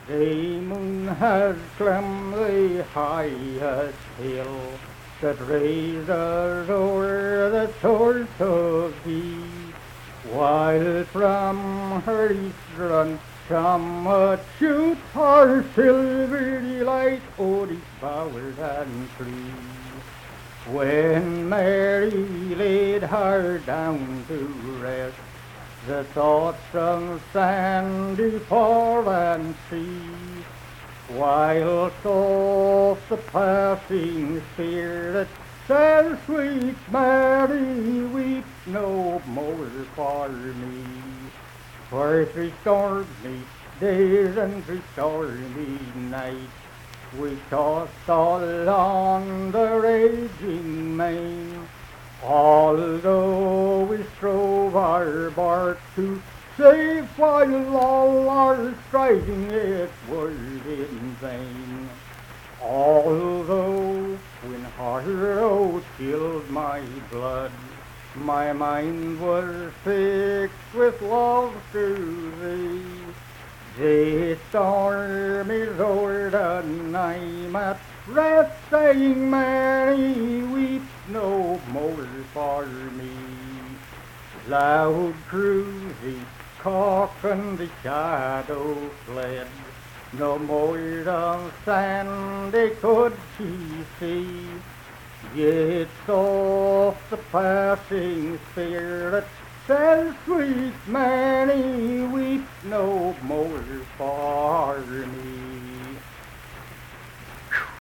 Unaccompanied vocal and fiddle music
Voice (sung)
Pleasants County (W. Va.), Saint Marys (W. Va.)